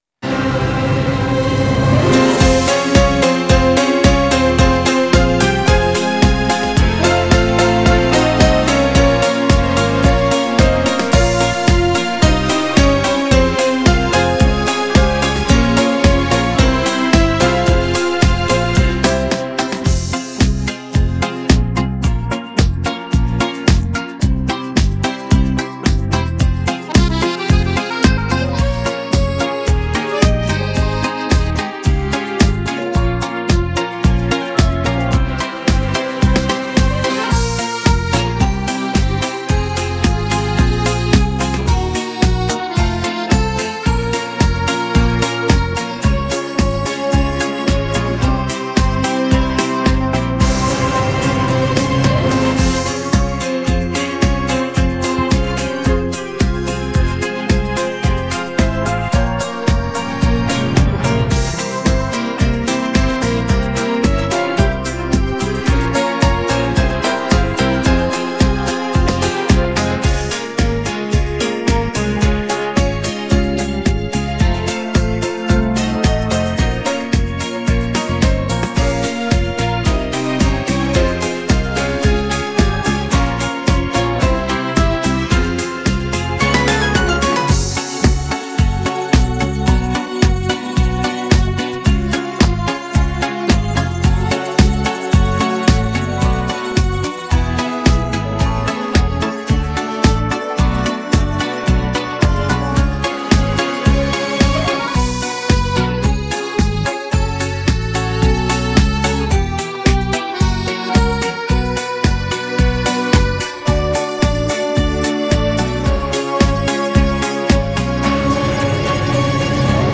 Качественный минус